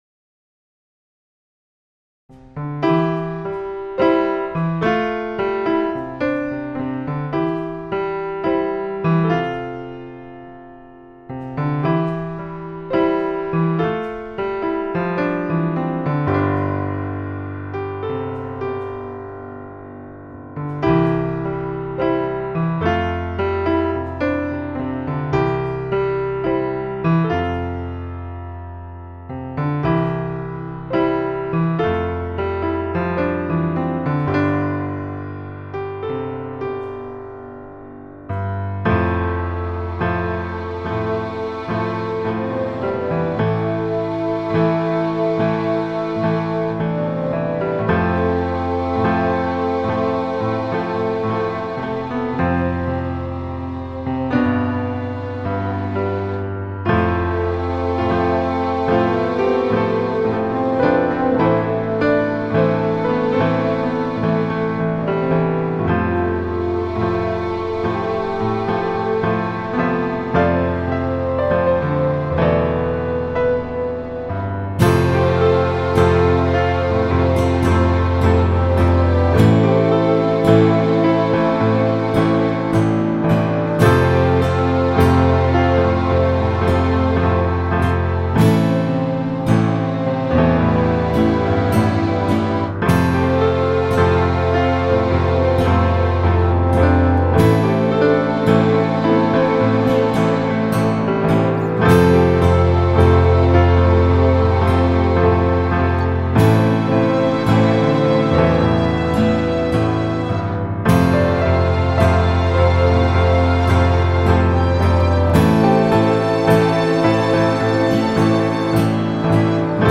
- Das Haltepedal eingesetzt (auch wenn ich noch keins gekauft habe, aber man kann es ja auch in Cubase einstellen). - mit der Lautstärke ein bisschen herumgespielt, damit die Streicher und das Horn lebendiger werden bzw. überhaupt zu hören - und die Gitarre ist nun nicht mehr ganz so dumpf.
Sie will sich irgendwie nicht so richtig einfügen in den Song. Der Plan ist übrigens, wenn alles schön ist, da noch Gesang drüber zu setzen.